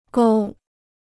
沟 (gōu): ditch; gutter.